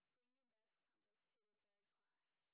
sp27_white_snr30.wav